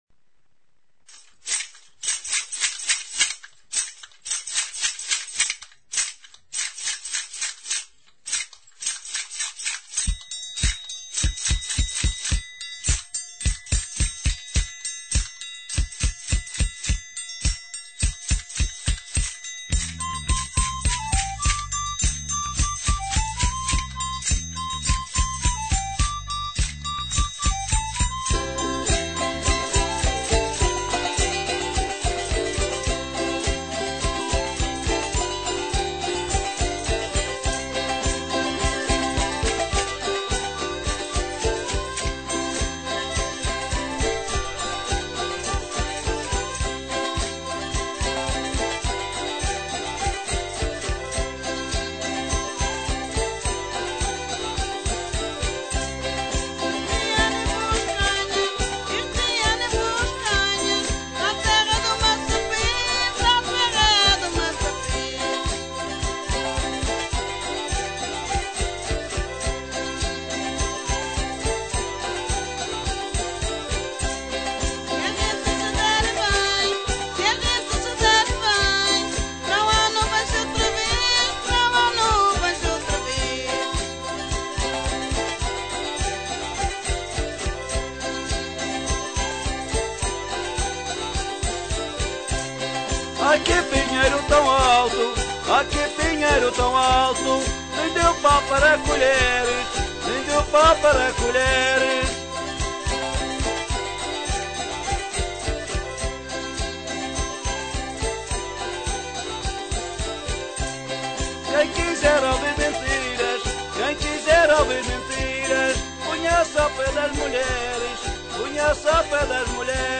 Vous pourrez apprécier nos danse traditionnelles de l'île de Madère mais également celles de nombreuses régions du Portugal.